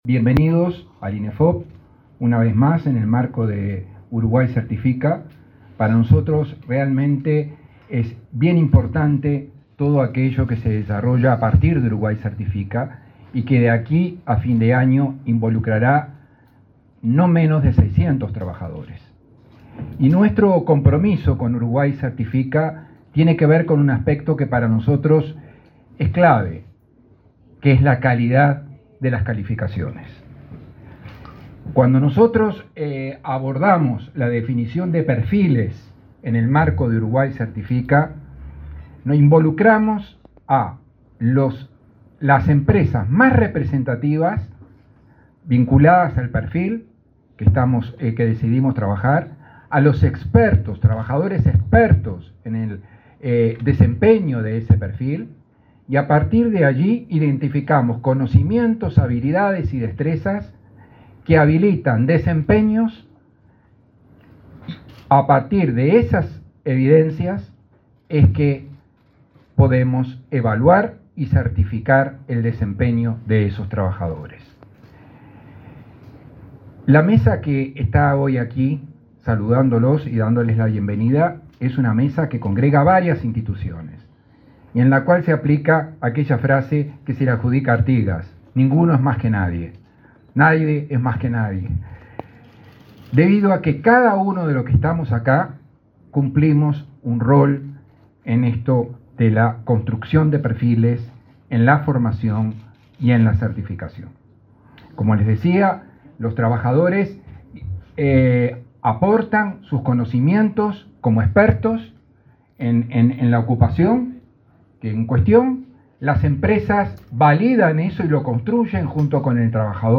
Palabra de autoridades en acto de Inefop